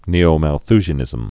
(nēō-măl-thzhə-nĭzəm, -môl-)